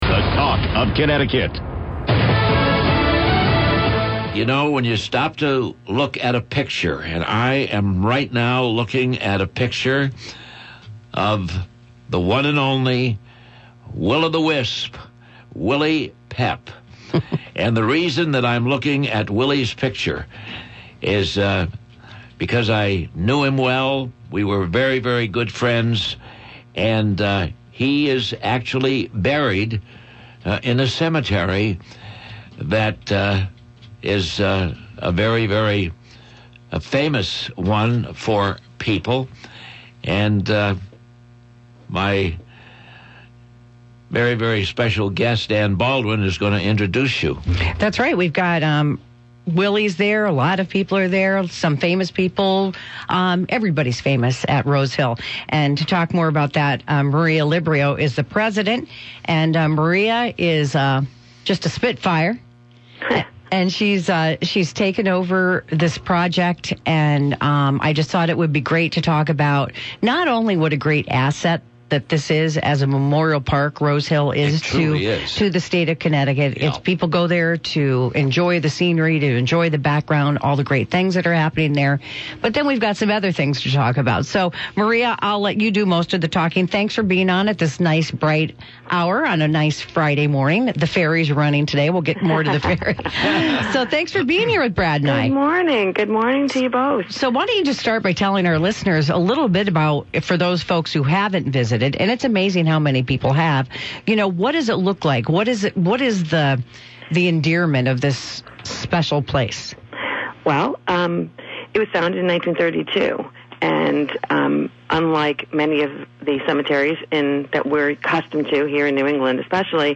Radio Interview – Rose Hill Memorial Park